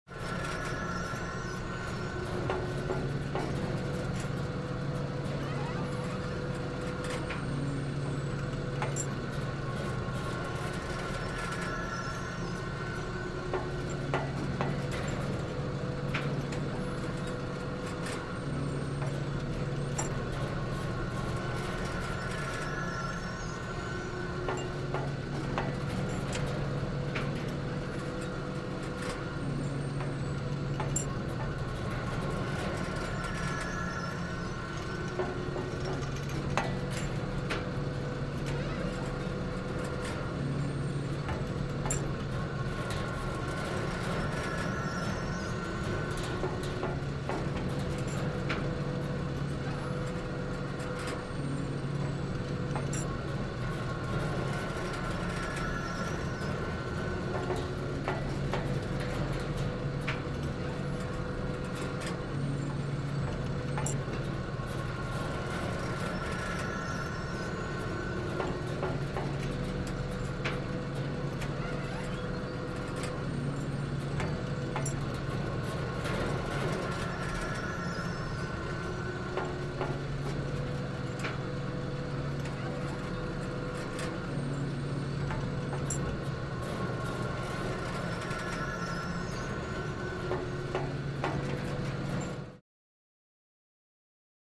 Oil Derrick; Running Steady, Distant Perspective